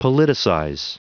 Prononciation du mot politicize en anglais (fichier audio)
Prononciation du mot : politicize